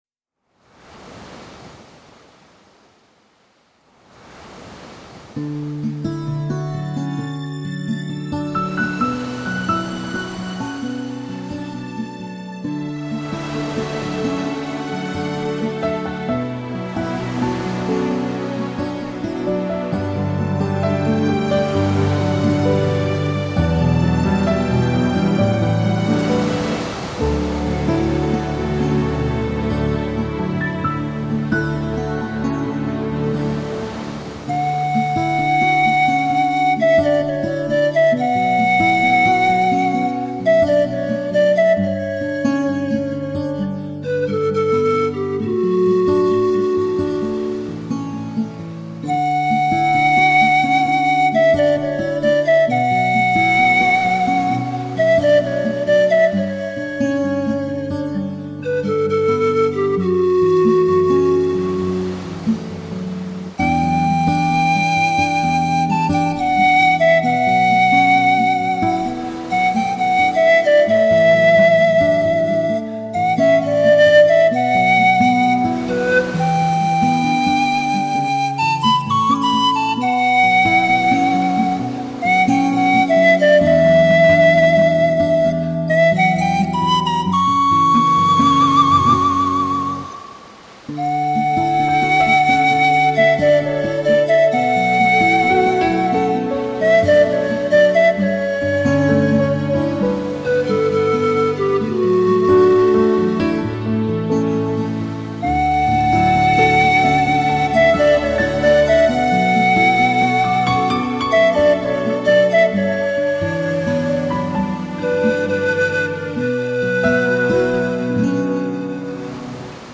排笛王子睽违3年最新力作，精酿最浓烈的幸福真味